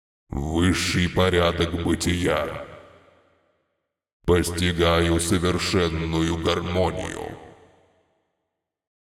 Муж, Пародия(Энигма (Dota 2))